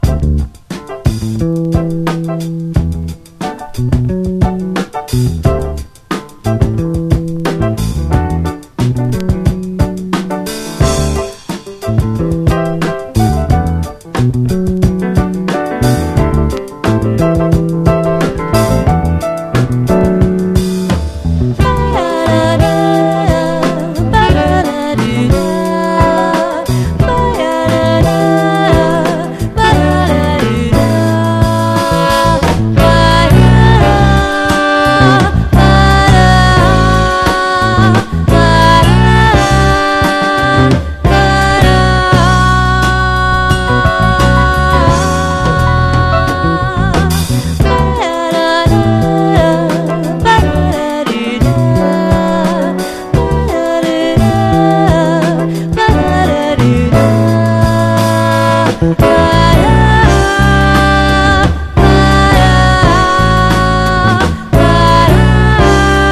JAZZ / JAZZ VOCAL
瑞々しいヴォーカルがキュートなデトロイトの才女による自主盤ジャズ/ファンク！